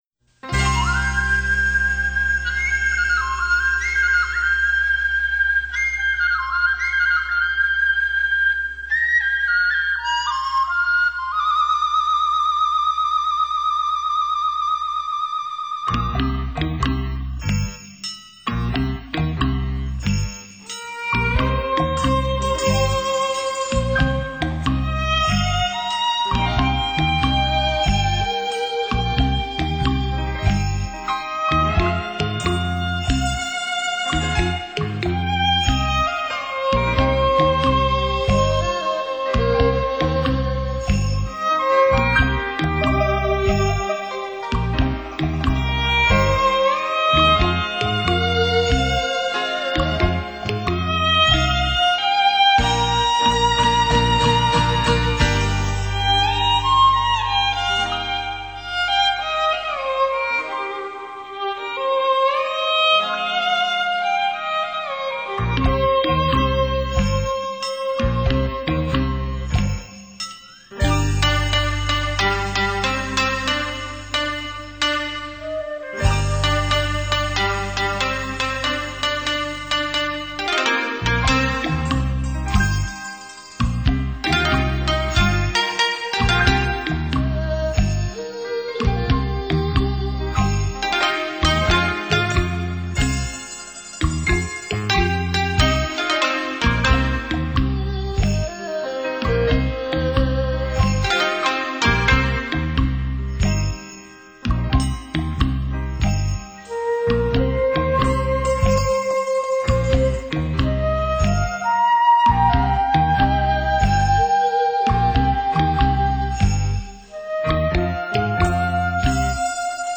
扬琴,琵琶,笛,箫,笙等配合西乐合奏出雅俗共赏的音乐~